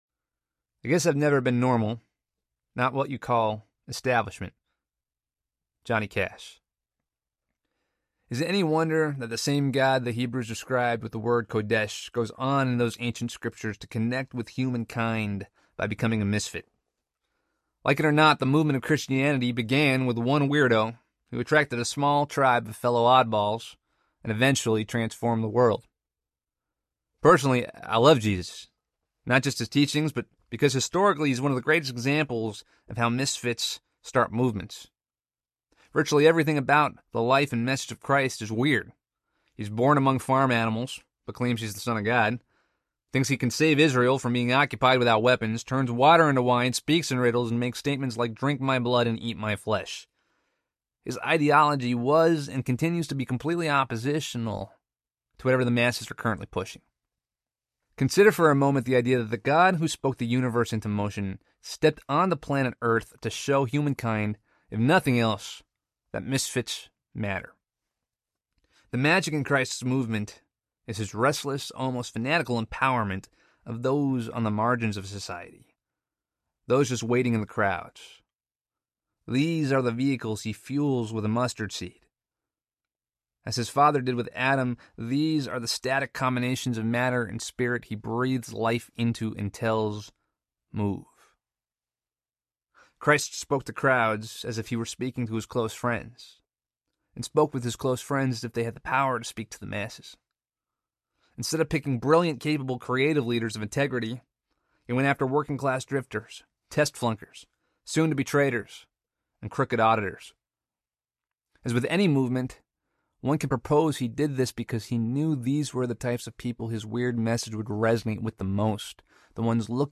Get Weird Audiobook